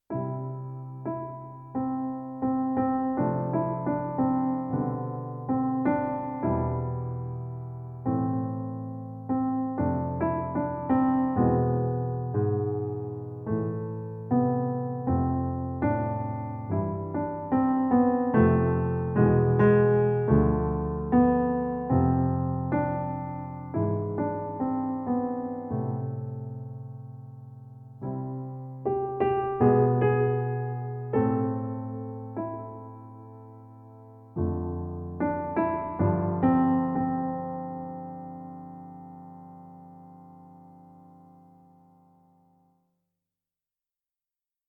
Slow Piano